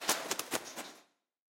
Шум посадки вороны